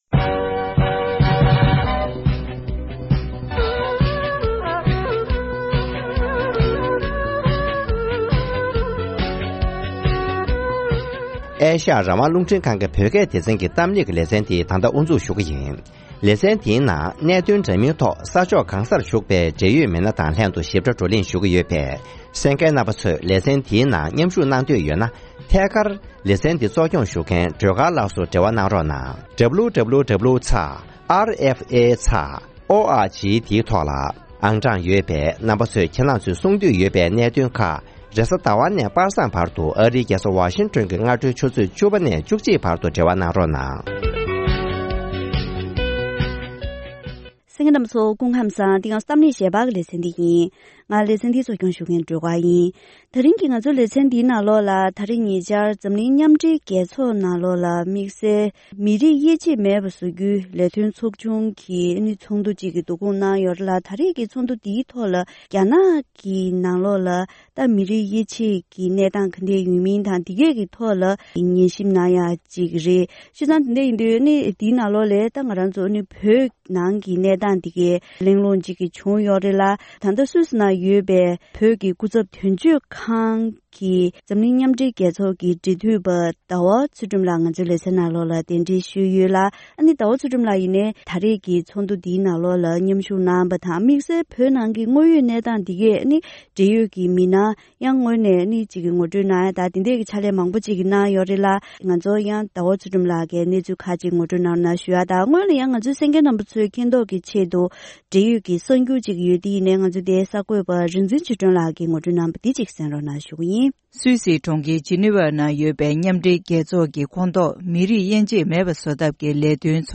༄༅། །ཐེངས་འདིའི་གཏམ་གླེང་ཞལ་པར་ལེ་ཚན་ནང་།